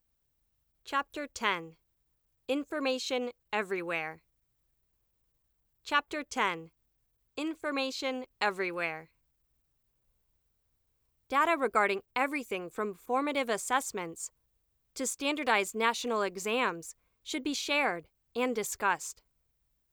I can’t hear any beeps either, but there is a buzzy resonance at 7.4kHz & 12kHz,
but that’s not the type of sound you posted: it would not be a continuous hiss with bursts of crackling.